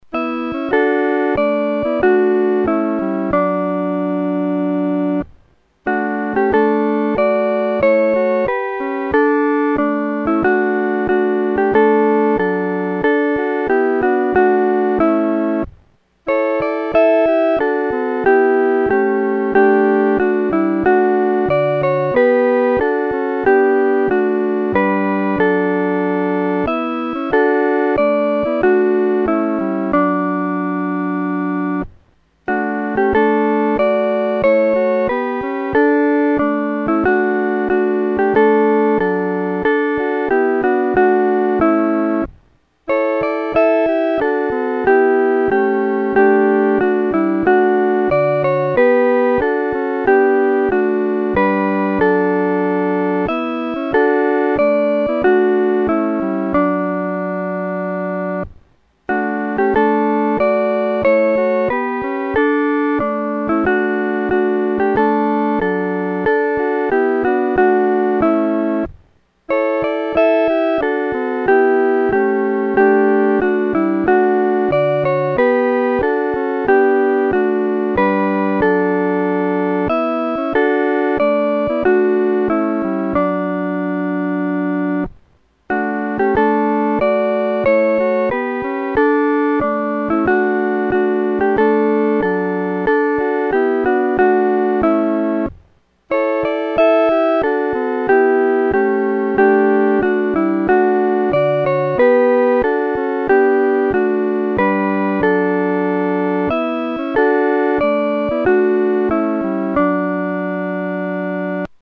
合奏（四声部）
牧人闻信-合奏（四声部）.mp3